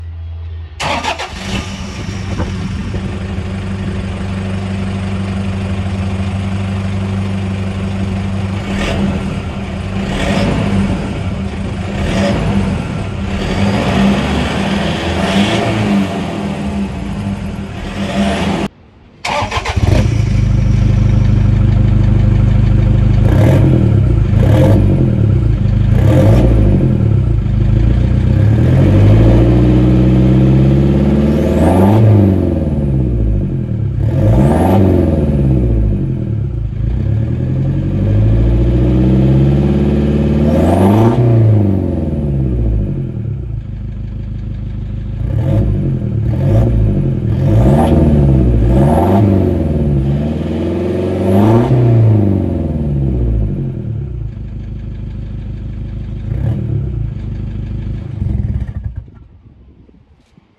Exhaust sounds comparison Hilux sound effects free download
Exhaust sounds comparison - Hilux stock vs 3” Redback Exhaust